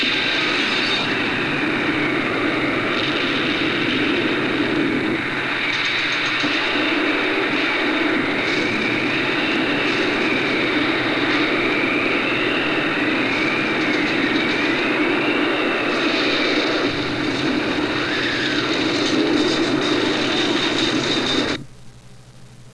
Some sound clips taken from the film.
A sound clip of the blast wave, when the Sheffield Bomb explodes.  In this scene we see buildings exploding, and masive volumes of damage caused by the burst.
SheffieldBomb.wav